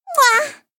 mwahh.mp3